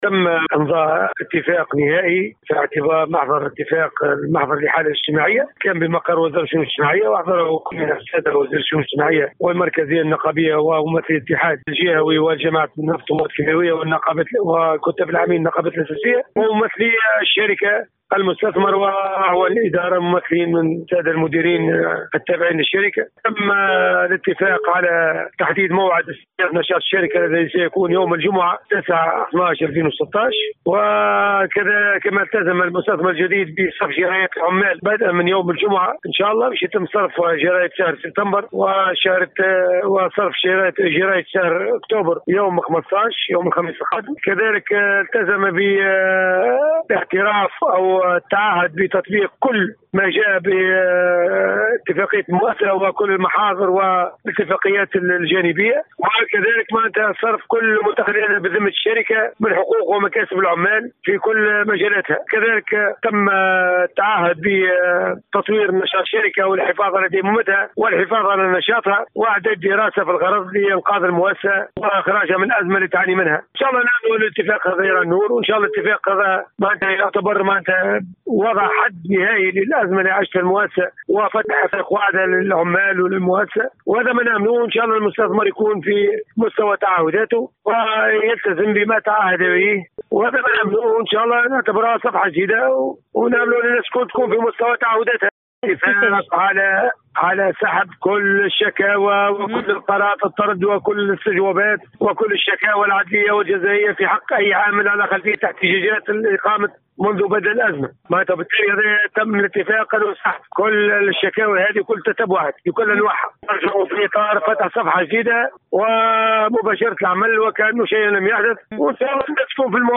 تفاصيل محضر الاتفاق في تصريح